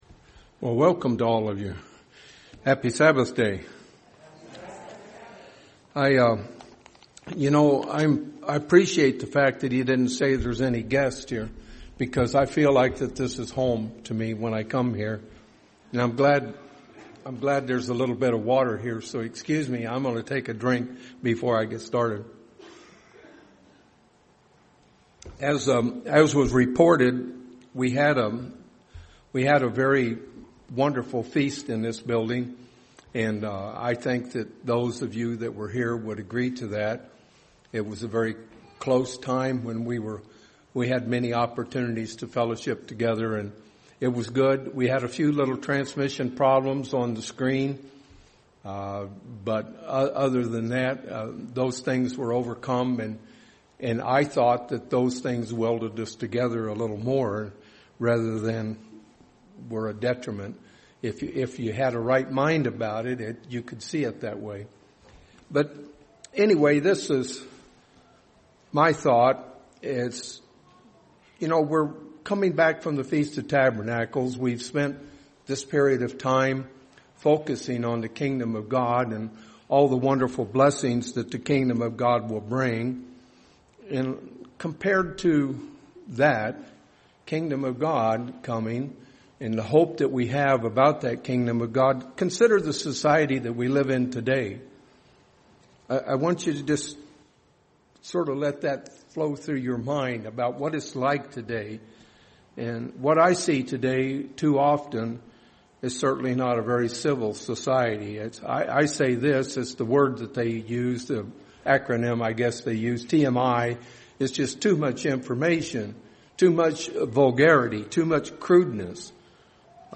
Sermons
Given in Phoenix East, AZ Phoenix Northwest, AZ